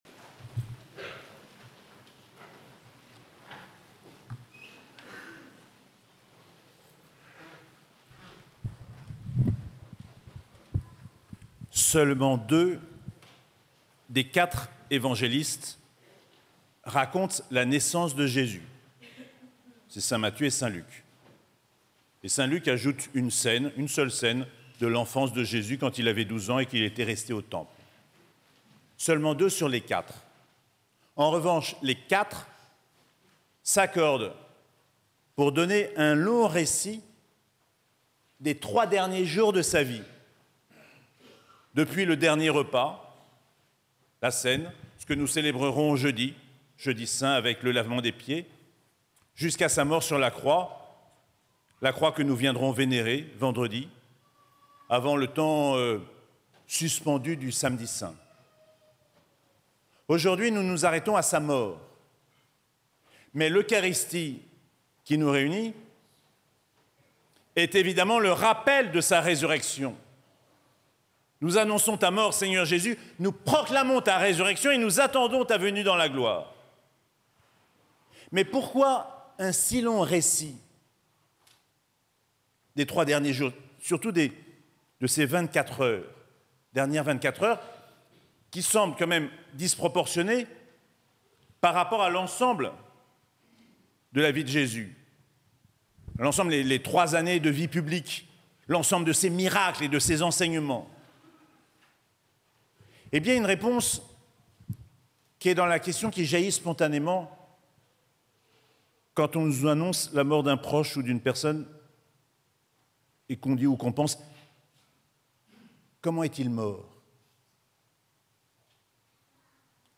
Messe des Rameaux et de la Passion - Dimanche 13 avril 2025